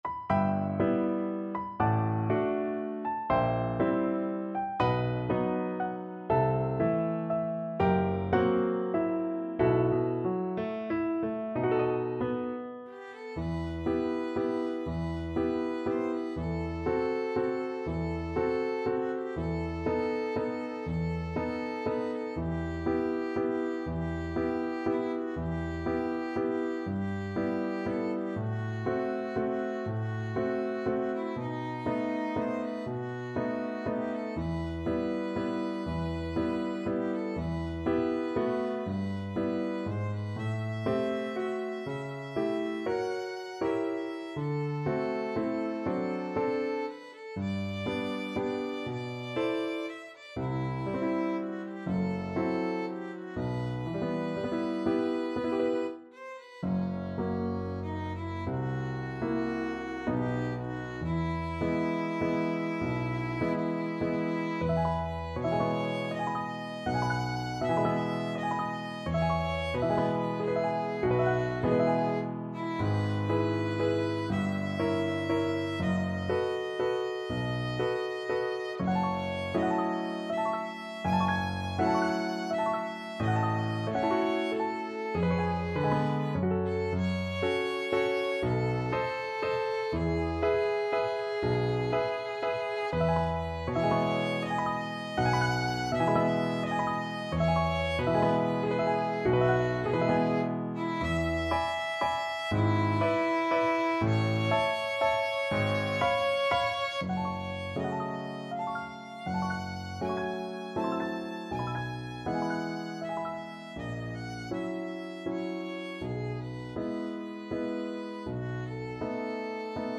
~ = 120 Lento
3/4 (View more 3/4 Music)
Classical (View more Classical Violin Music)